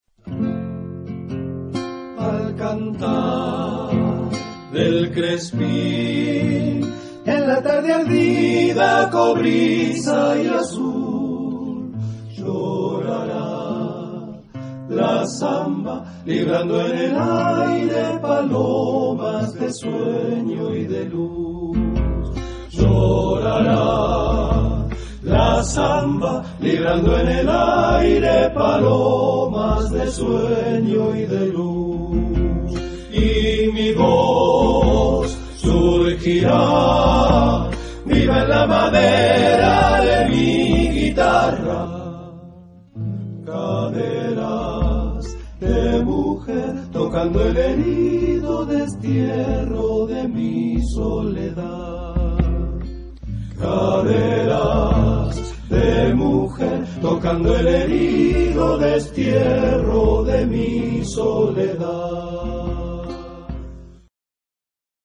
Type of Choir: TTBBB  (5 men voices )